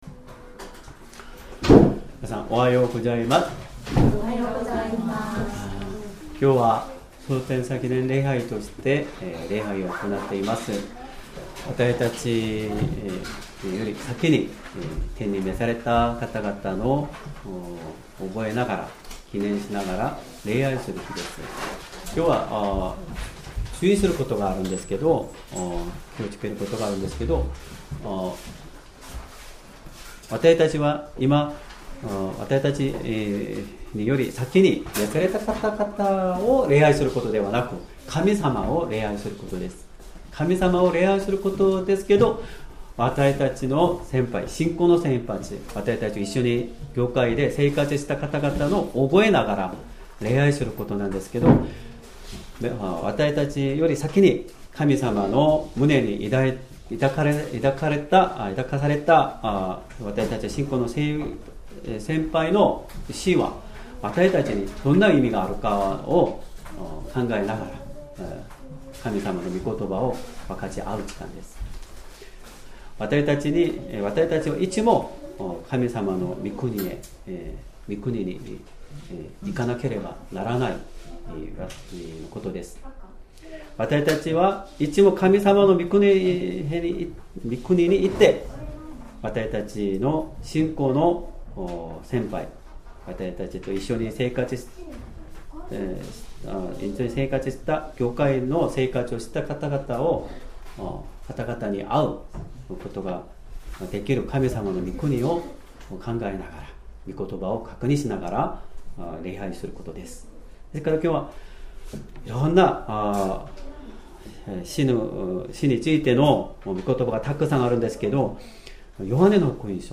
Sermon
2025年11月23日 主日礼拝 説教 「永遠なる神の御国へ」 聖書 ヨハネの黙示録22章１～21節 22:1 御使いはまた、水晶のように輝く、いのちの水の川を私に見せた。